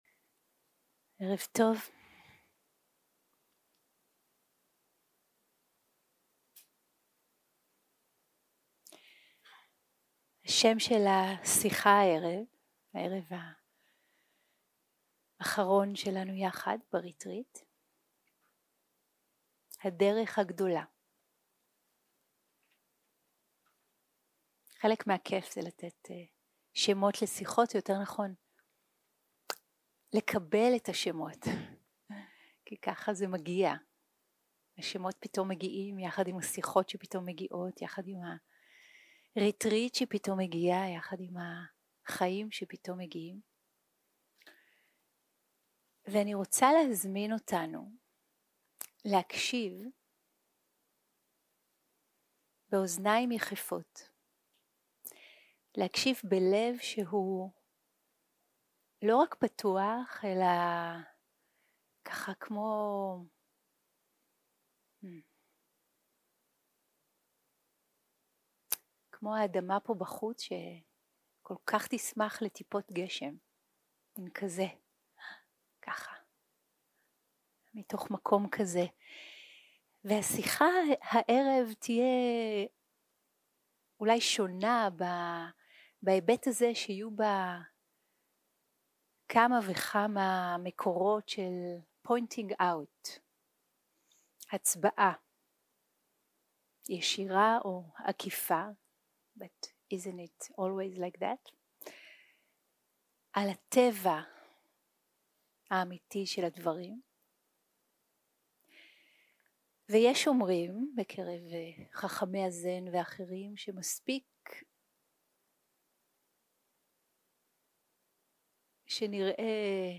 הקלטה 14 - יום 5 - ערב - שיחת דהרמה - הדרך הגדולה
Dharma type: Dharma Talks